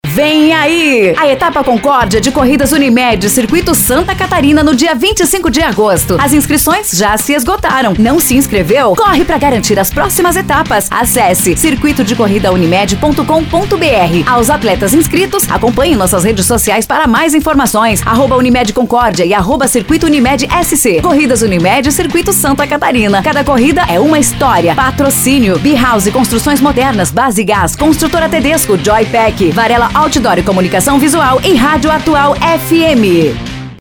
Estilo Animado: